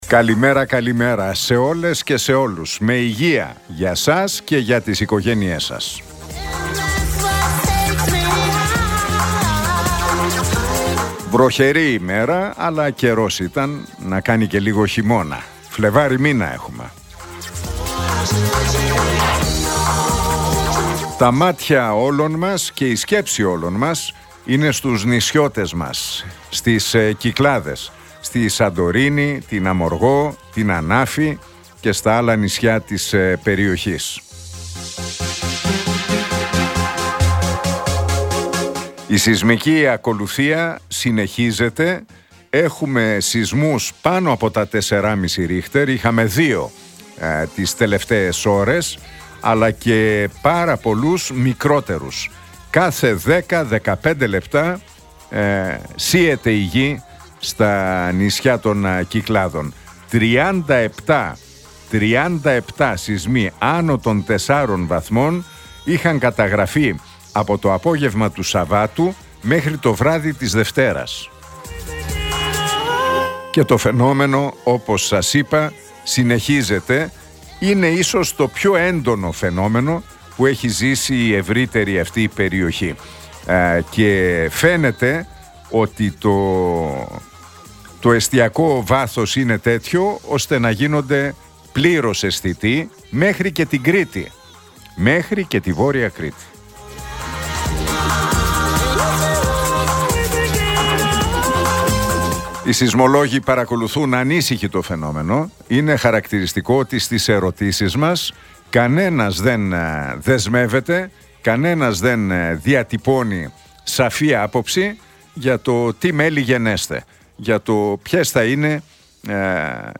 Ακούστε το σχόλιο του Νίκου Χατζηνικολάου στον ραδιοφωνικό σταθμό RealFm 97,8, την Τρίτη 4 Φεβρουαρίου 2025.